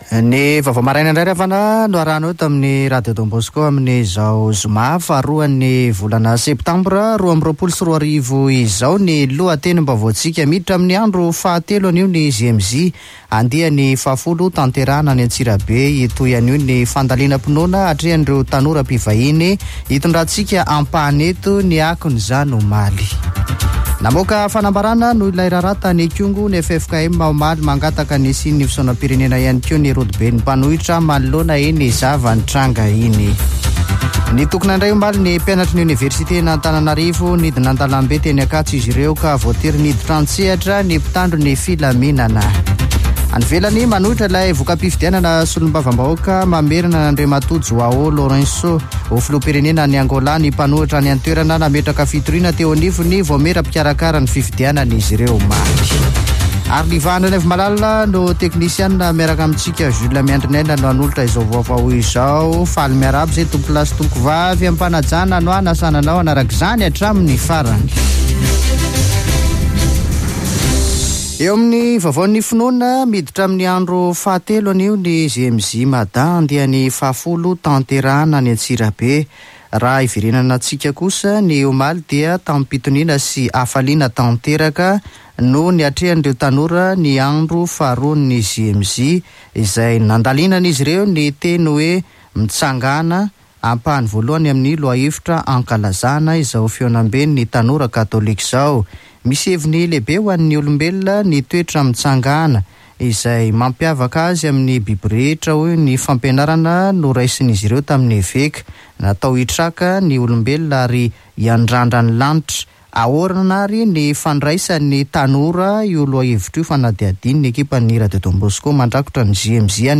[Vaovao maraina] Zoma 2 septambra 2022